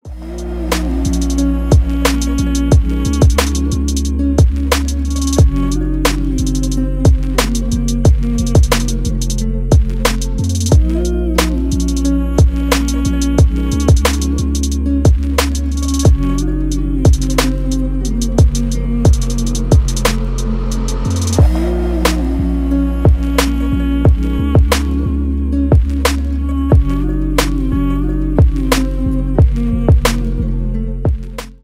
Ремикс
спокойные